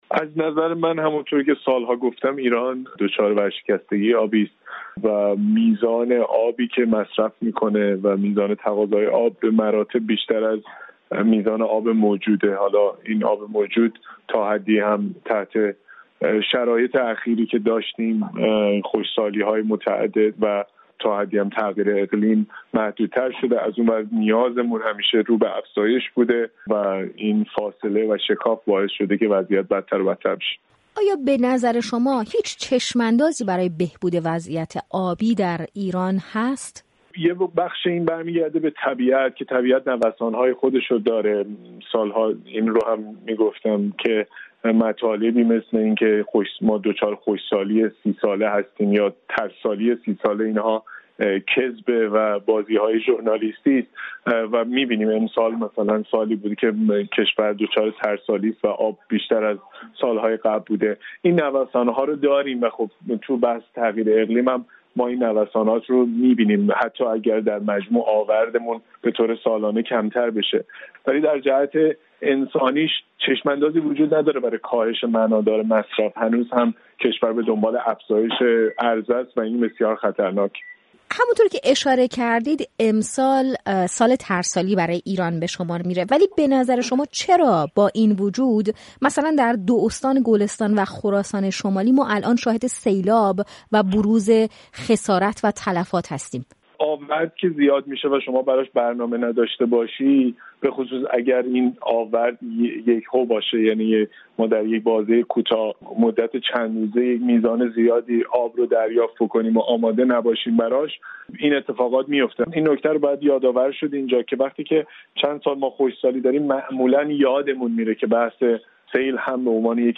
گفت‌وگو با کاوه مدنی، معاون پیشین سازمان محیط زیست ایران، به مناسبت روز جهانی آب